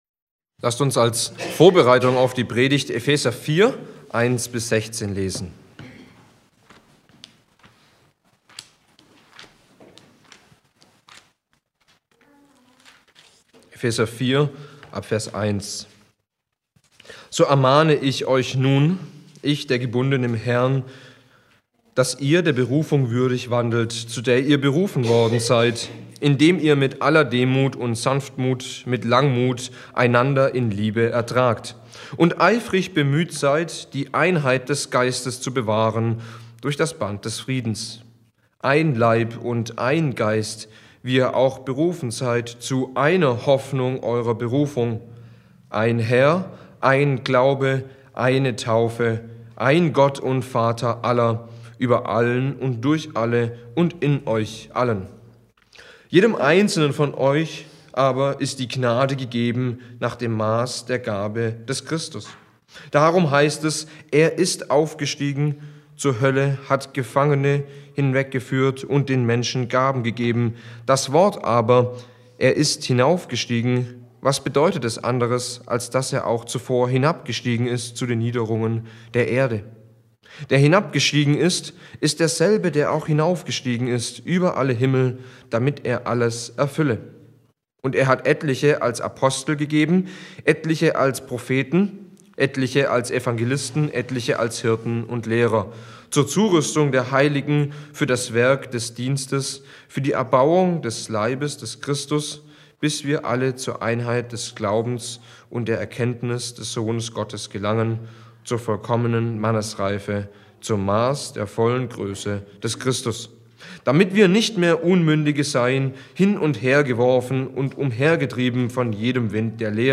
Die Predigt führt uns hinein in Gottes leidenschaftlichen Ruf zur Einheit seiner Gemeinde.